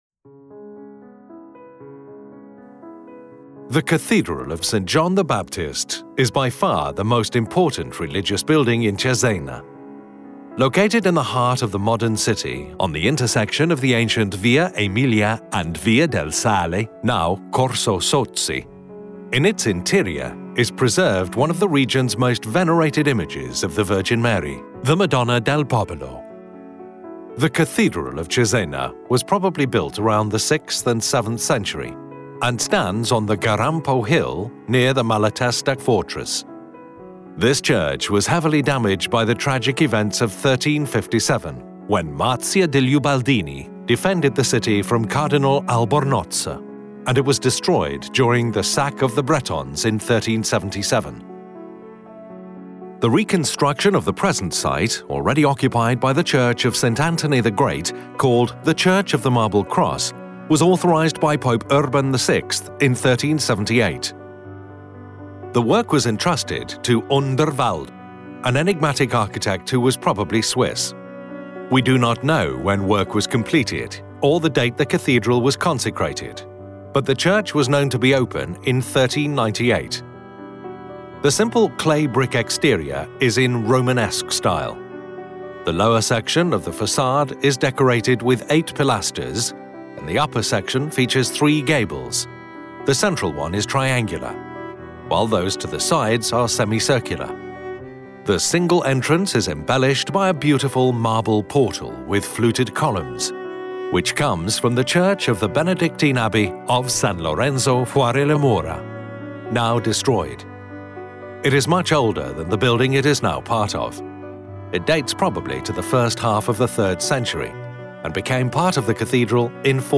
Audioguida